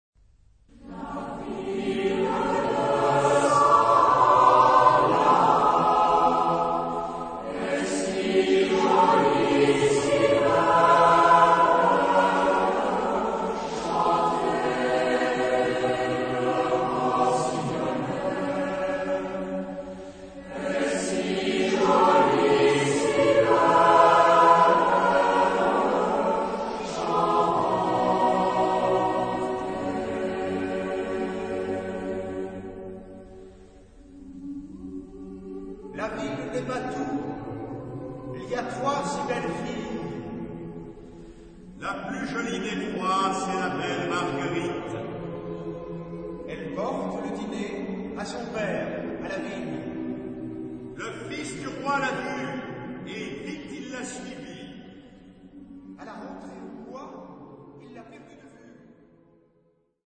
Genre-Stil-Form: Volkstümlich ; weltlich
Chorgattung: SATB  (4 gemischter Chor Stimmen )